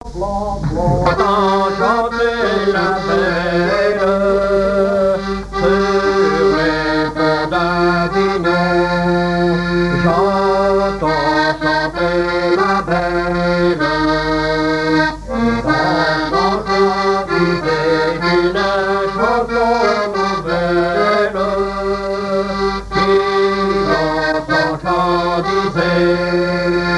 Mémoires et Patrimoines vivants - RaddO est une base de données d'archives iconographiques et sonores.
circonstance : fiançaille, noce
Genre laisse
Enquête Tap Dou Païe et Sounurs, sections d'Arexcpo en Vendée
Pièce musicale inédite